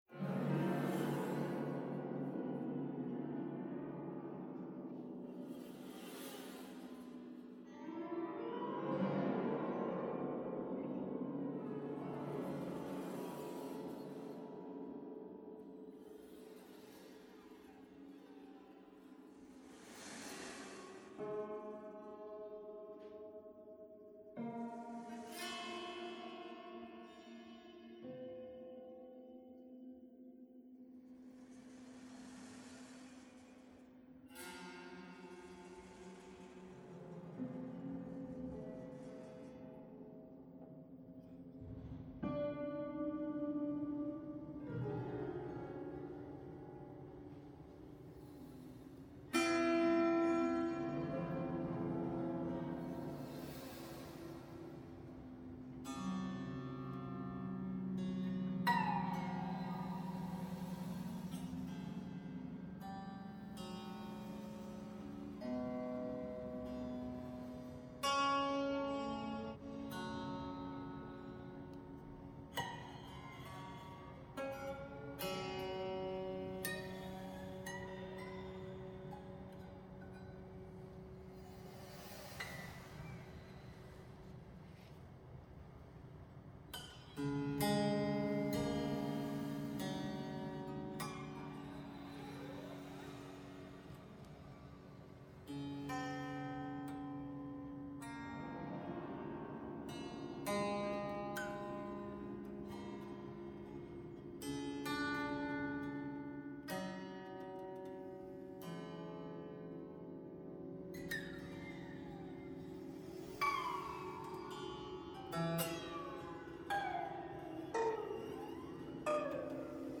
• Dropped piano
Composed improvisation #1 for piano remains and computer
01-Composed-improvisation-1-for-pia.mp3